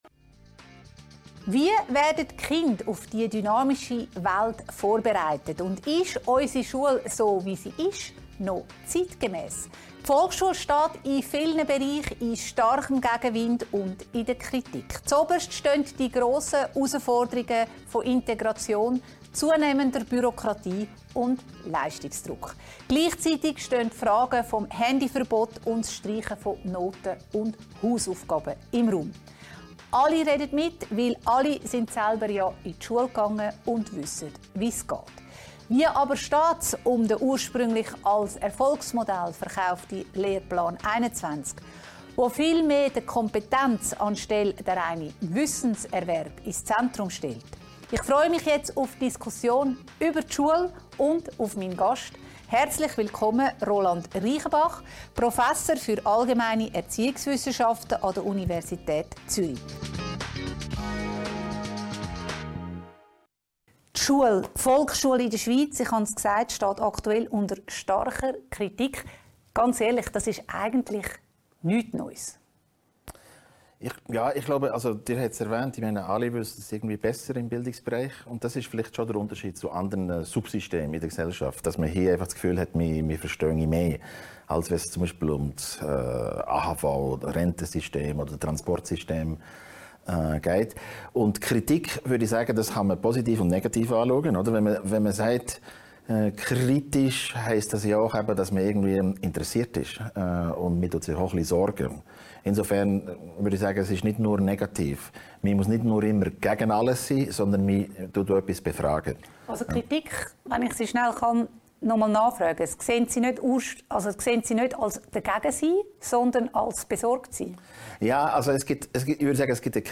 im Gespräch mit Erziehungswissenschaftler